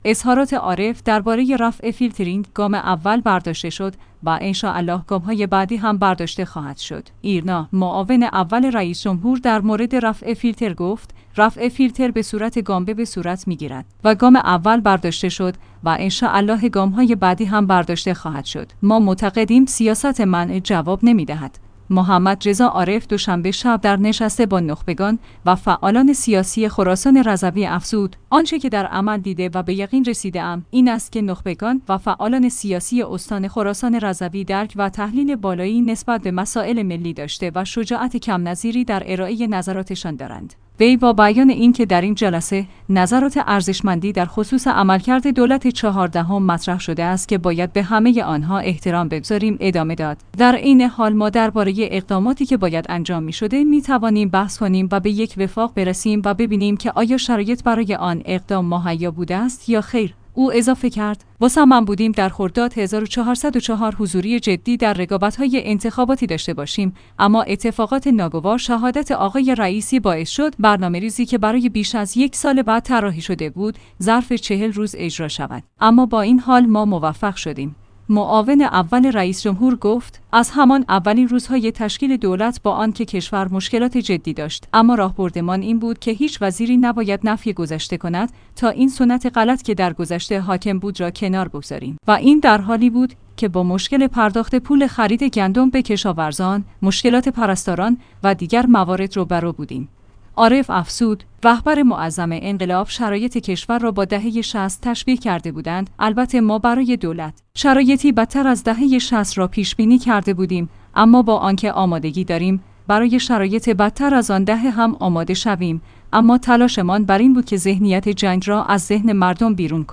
ما معتقدیم سیاست منع جواب نمی‌دهد. محمد رضا عارف دو شنبه شب در نشست با نخبگان و فعالان سیاسی خراسان رضوی افزود: آنچه که در عمل دیده و به یق